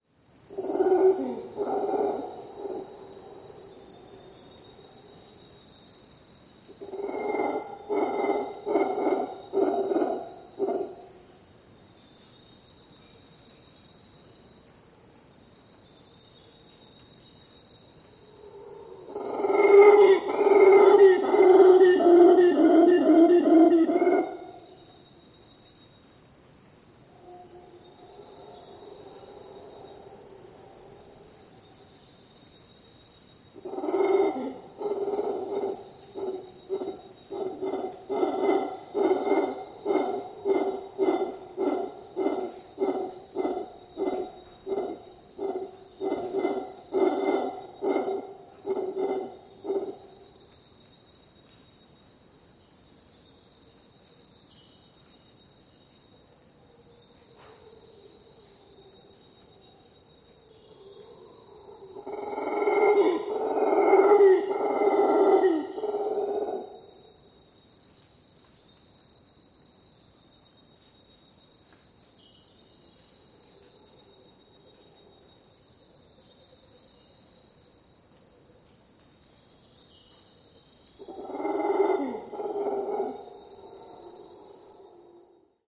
На этой странице собраны разнообразные звуки ревунов — от громких рыков до отдаленных эхо в джунглях.
Крик ревуна в густых джунглях